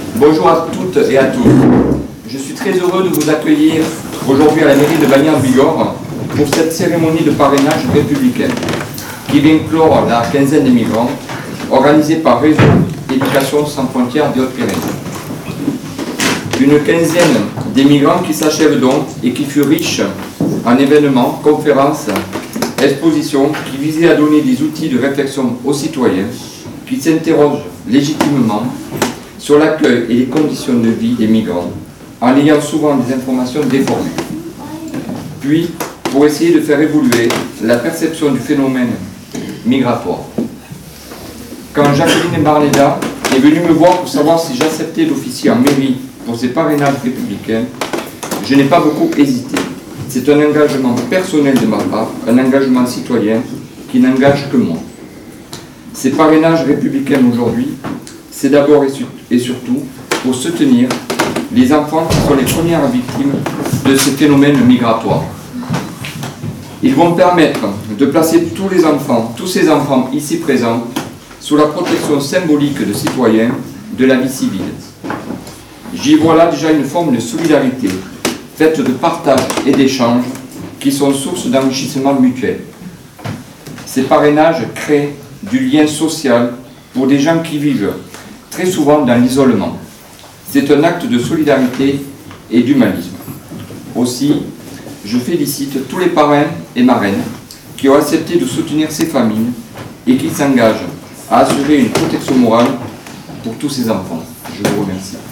Jean-Bernard Sempastous, maire de Bagnères-de-Bigorre accueille les familles, les parrains et marraines dans une salle du conseil comble :
Accueil-du-maire.mp3